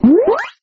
speedup.mp3